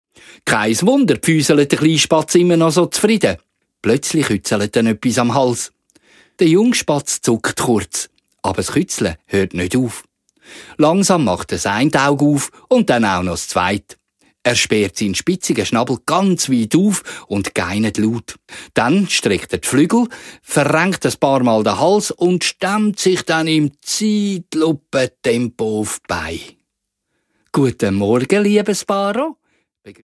Hörspiel-Album (ohne Kalender)